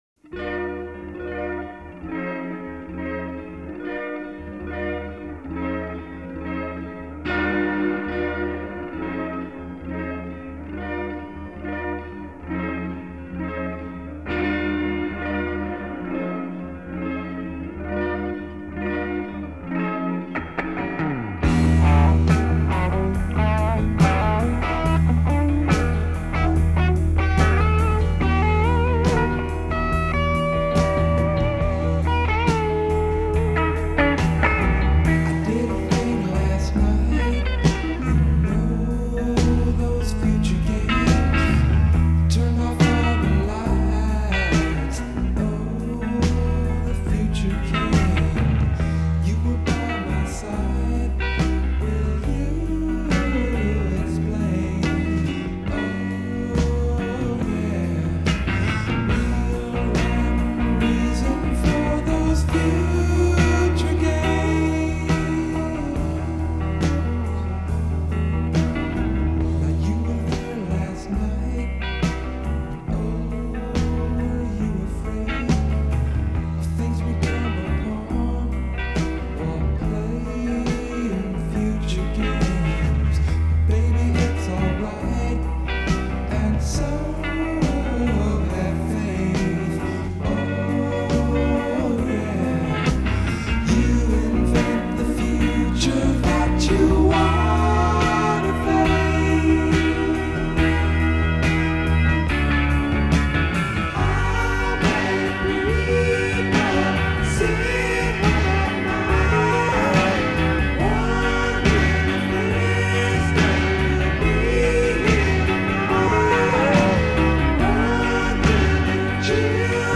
рок-музыка